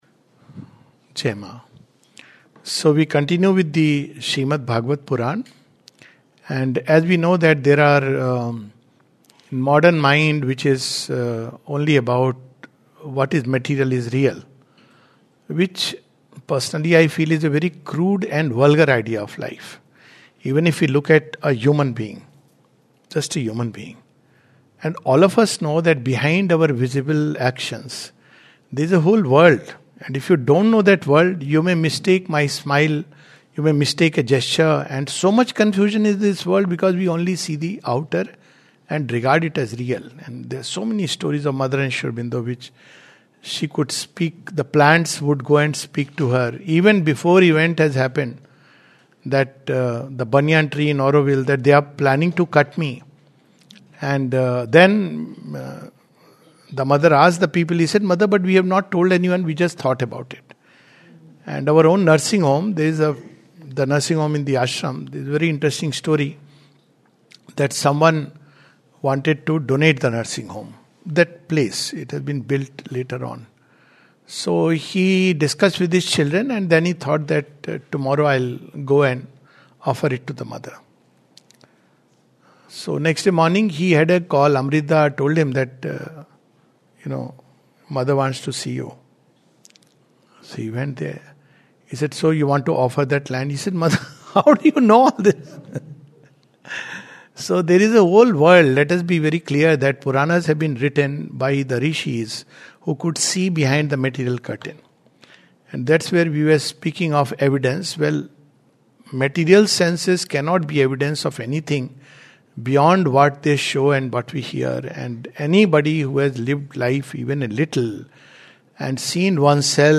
Today we take up two stories of Prince Dhruv and of King Prithu. A talk